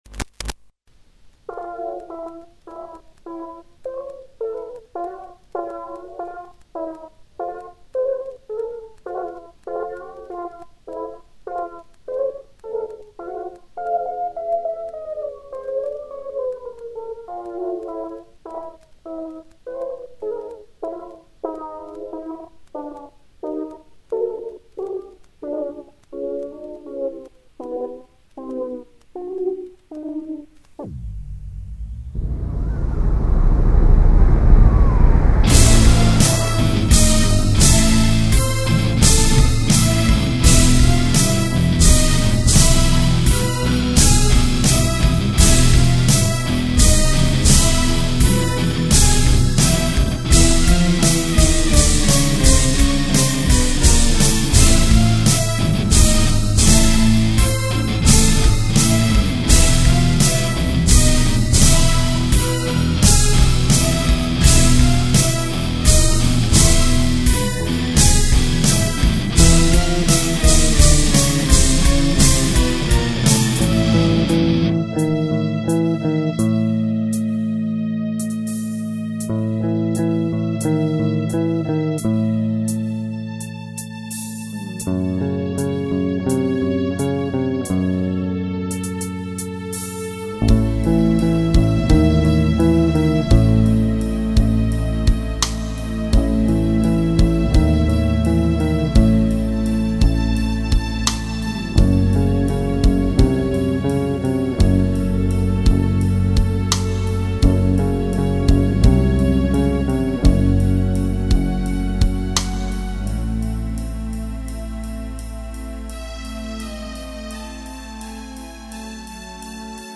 Гитары, бас, клавиши, перкуссия, голос